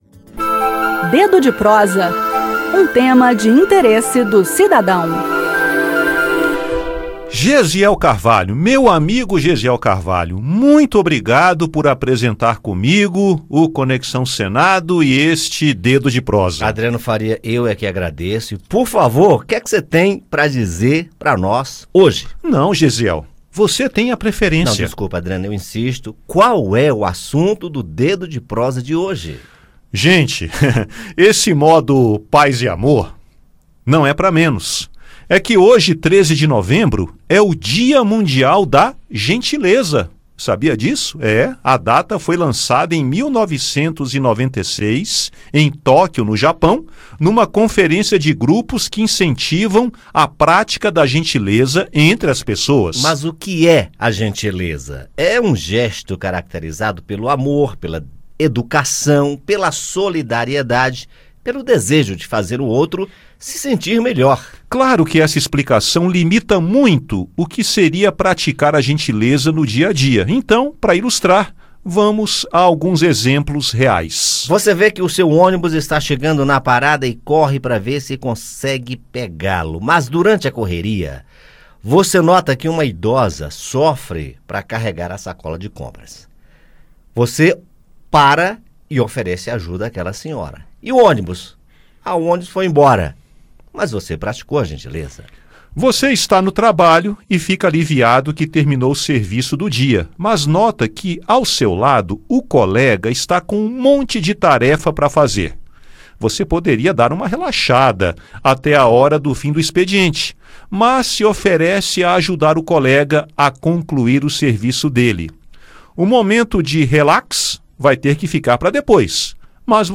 Gentileza pode ser um gesto de educação, de solidariedade ou de cuidado, é o desejo de fazer o outro se sentir melhor. Ouça no bate-papo como pequenos gestos de gentileza podem fazer bem, tornando a vida em sociedade menos dura e mais acolhedora.